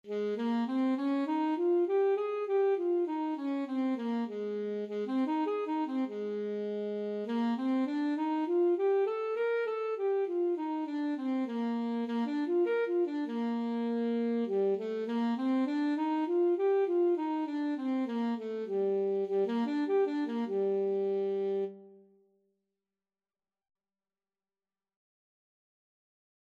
Classical Saxophone scales and arpeggios - Grade 1 Alto Saxophone version
4/4 (View more 4/4 Music)
G4-Bb5
Ab major (Sounding Pitch) F major (Alto Saxophone in Eb) (View more Ab major Music for Saxophone )
saxophone_scales_grade1_ASAX.mp3